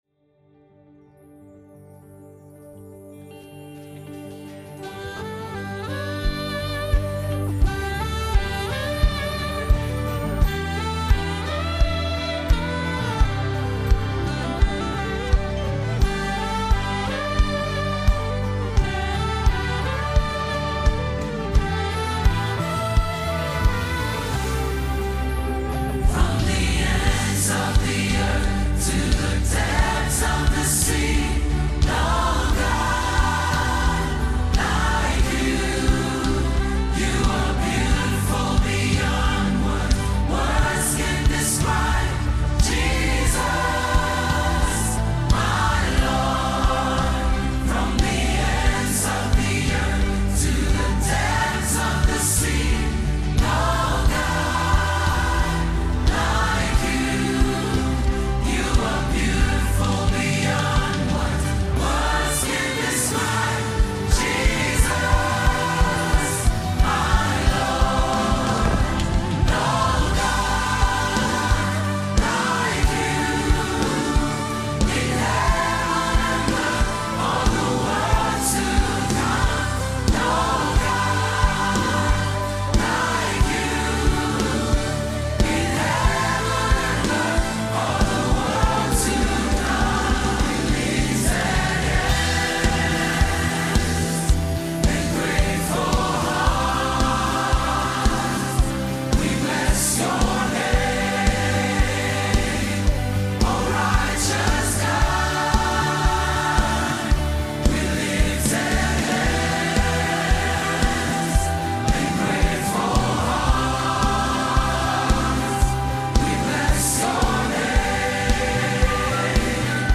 Lyrics, Medleys